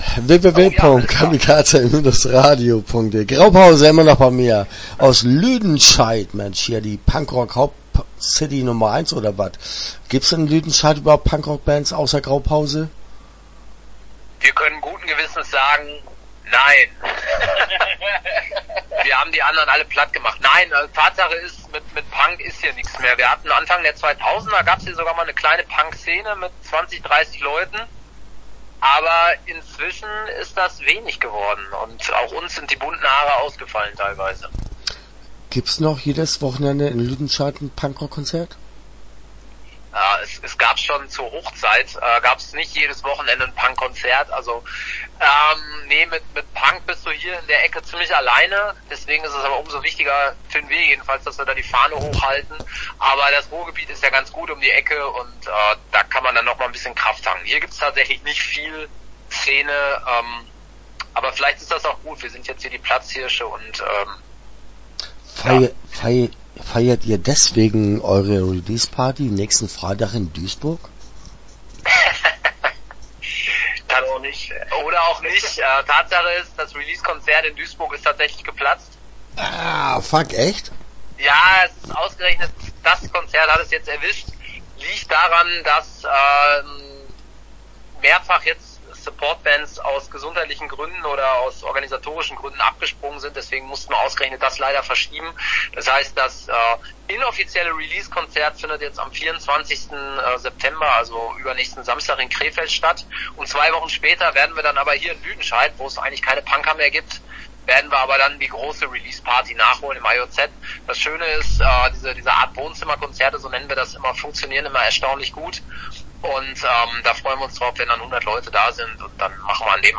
Graupause - Interview Teil 1 (10:05)